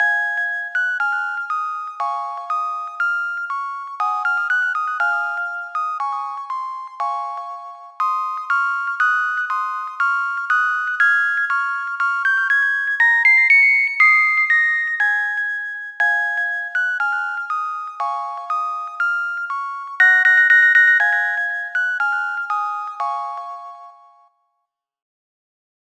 Kategorien Weihnachten